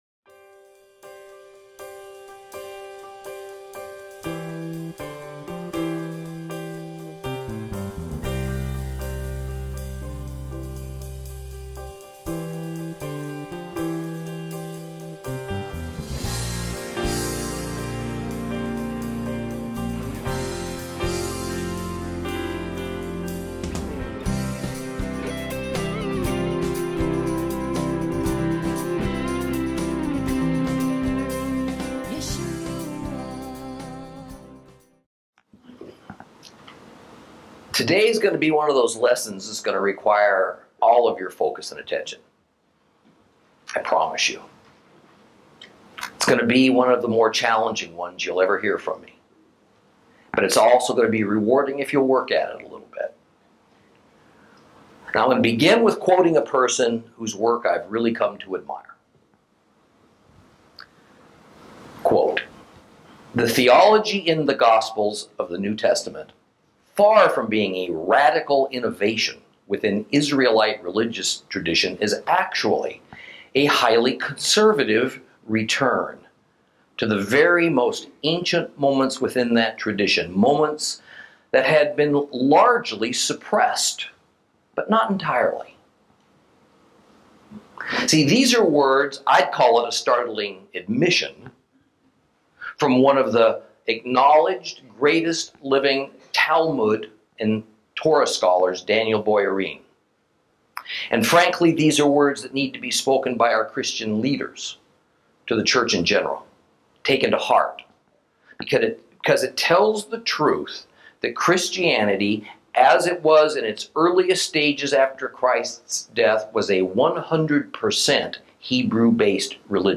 Lesson 19 Ch7 - Torah Class